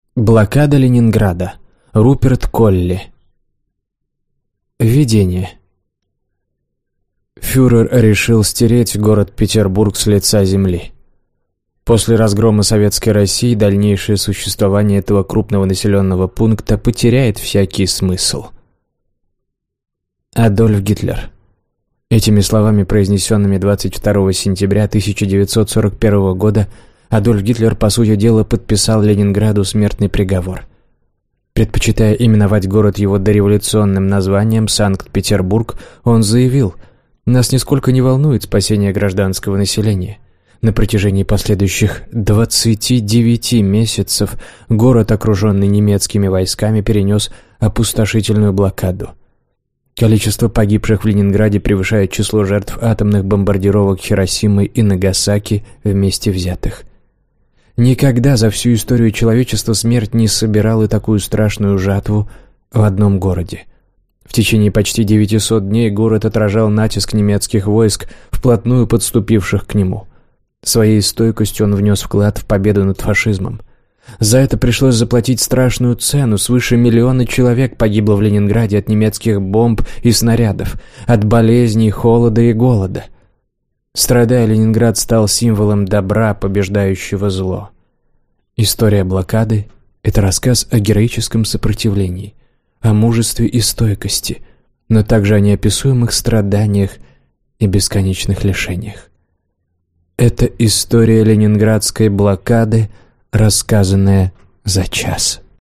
Аудиокнига Блокада Ленинграда | Библиотека аудиокниг